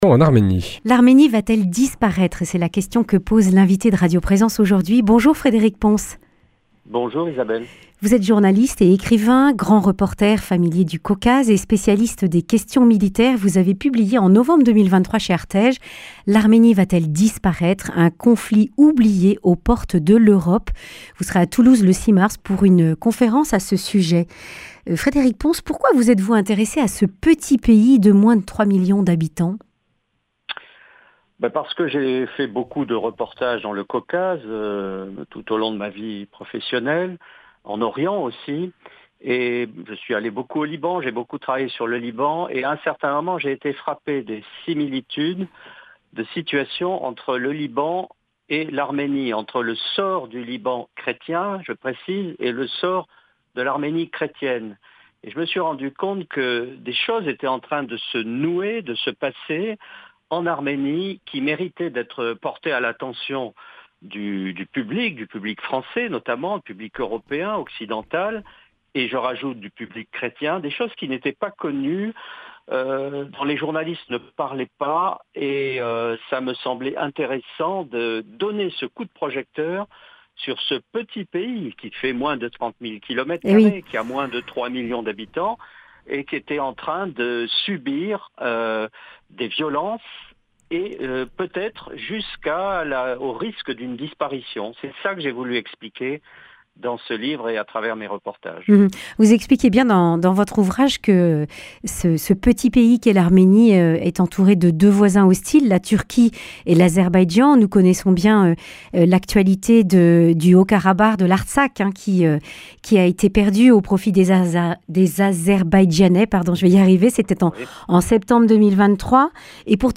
Accueil \ Emissions \ Information \ Régionale \ Le grand entretien \ L’Arménie va-t-elle disparaître ?